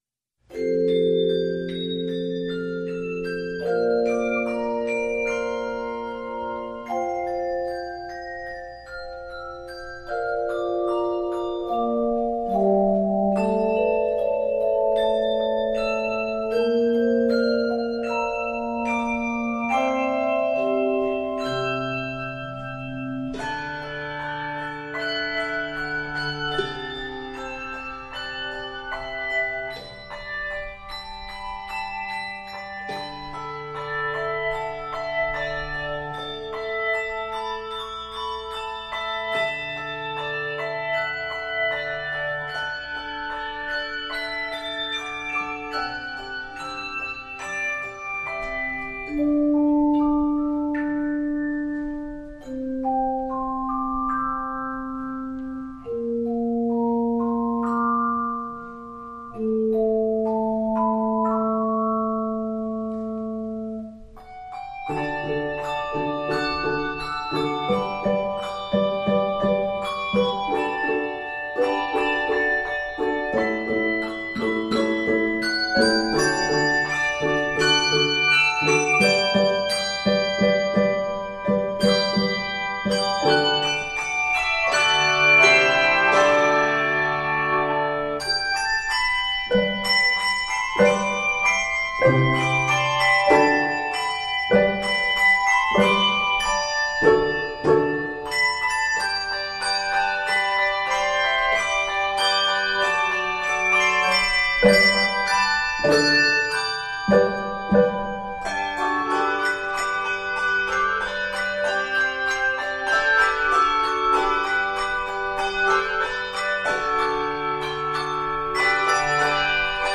Introduction is in the key of F Major